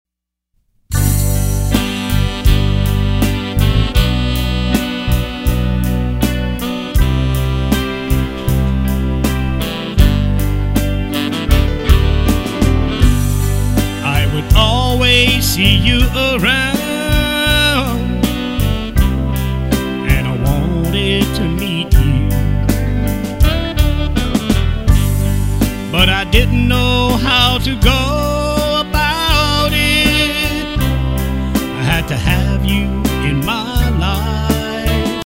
Genre: Blues/R&B.